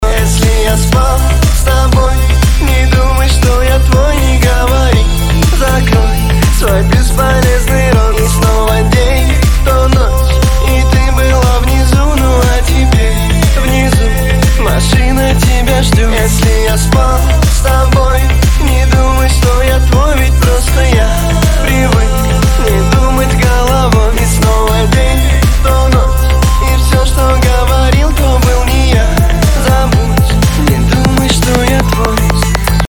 • Качество: 320, Stereo
мужской голос
басы
качающие